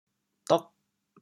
“独”字用潮州话怎么说？
dog8.mp3